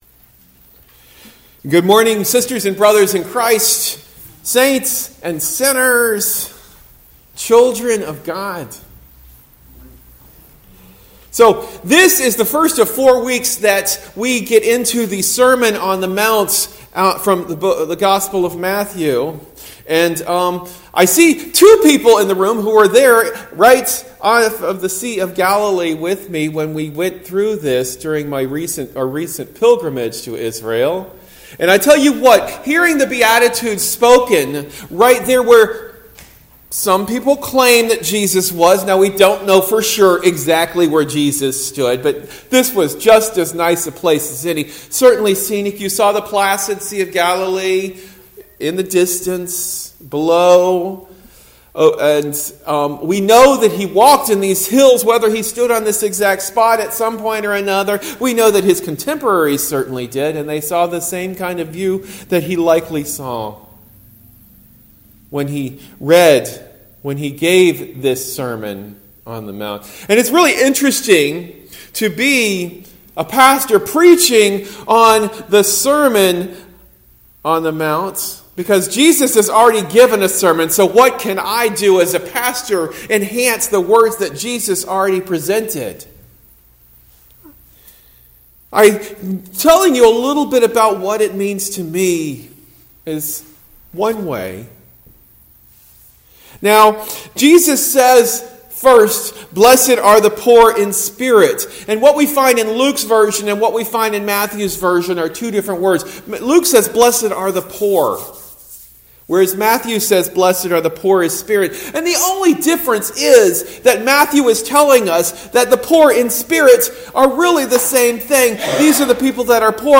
Sermon delivered at Lutheran Church of the Cross in Berkeley.